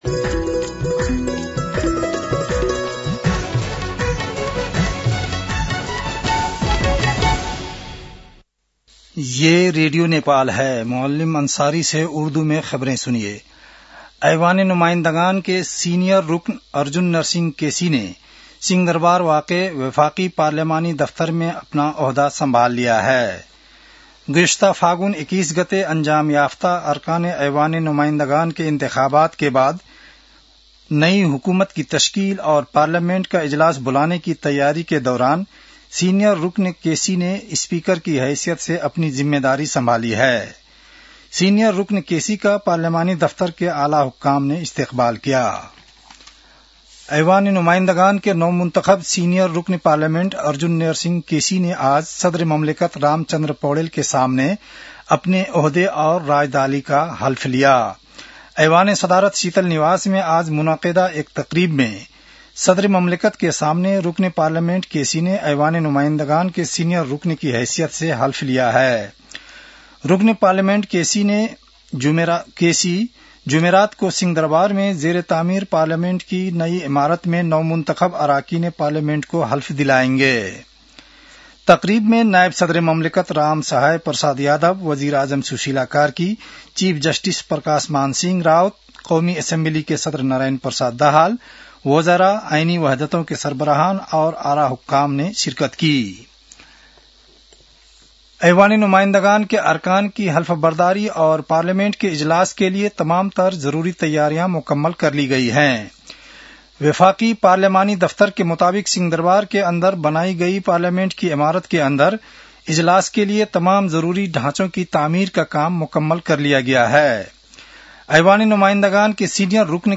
उर्दु भाषामा समाचार : ११ चैत , २०८२